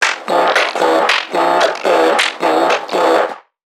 NPC_Creatures_Vocalisations_Infected [69].wav